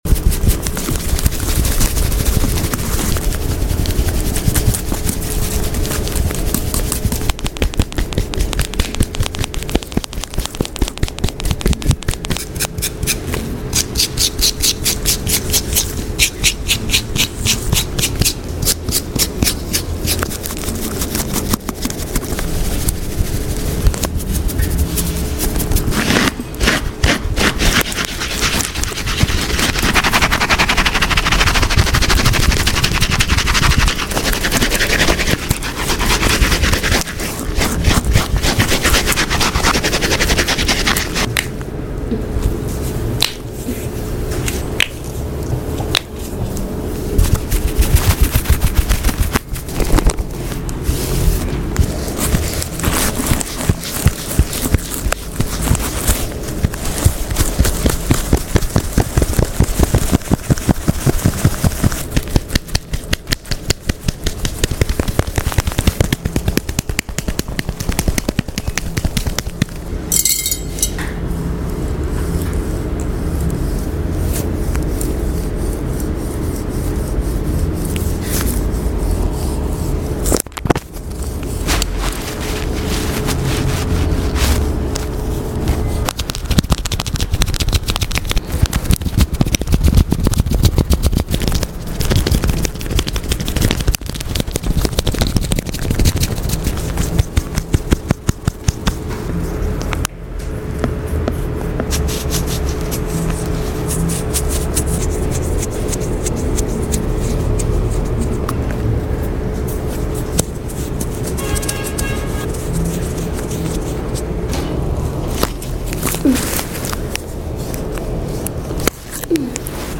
ASMR💫AGGRESSIVE HEAD SCALP SCRATCH ,EAR sound effects free download